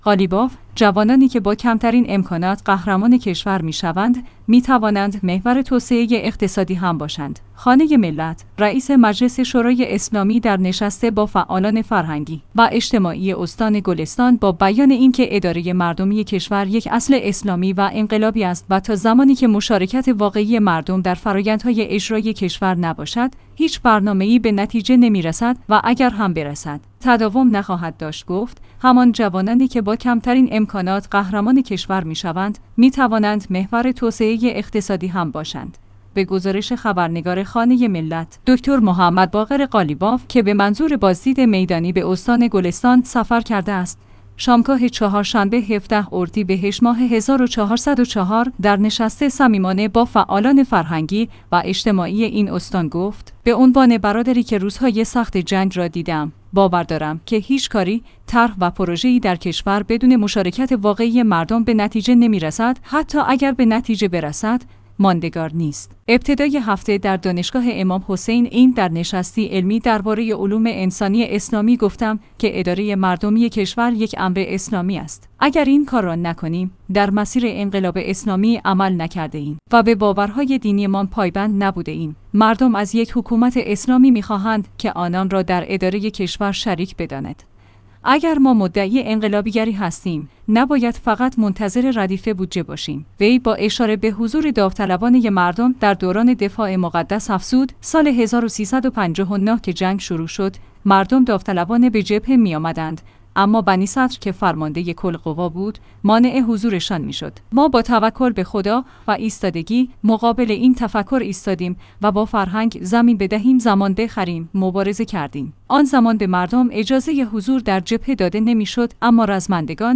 خانه ملت/ رئیس مجلس شورای اسلامی در نشست با فعالان فرهنگی و اجتماعی استان گلستان با بیان اینکه اداره مردمی کشور یک اصل اسلامی و انقلابی است و تا زمانی که مشارکت واقعی مردم در فرآیندهای اجرایی کشور نباشد، هیچ برنامه‌ای به نتیجه نمی‌رسد و اگر هم برسد، تداوم نخواهد داشت گفت: همان جوانانی که با کمترین ا